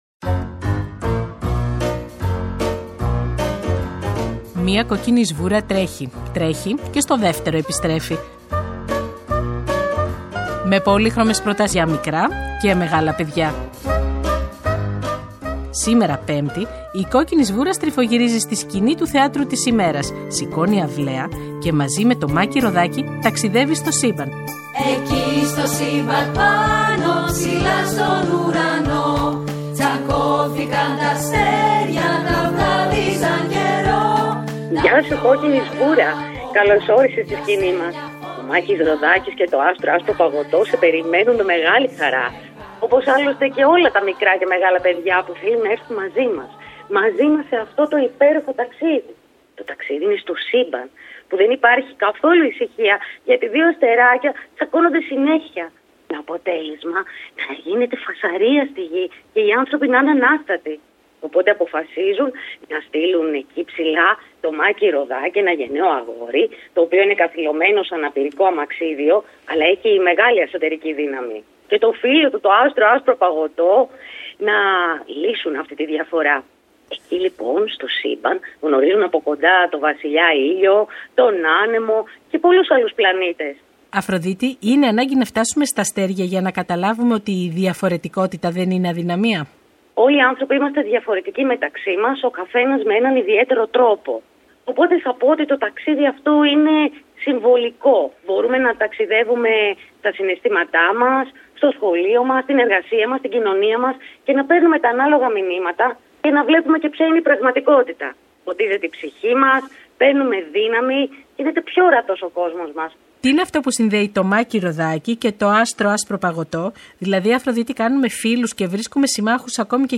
Η «Κόκκινη Σβούρα» στο Δεύτερο Πρόγραμμα – Πέμπτη 01 Φεβρουαρίου 2024
Επιμέλεια – Παρουσίαση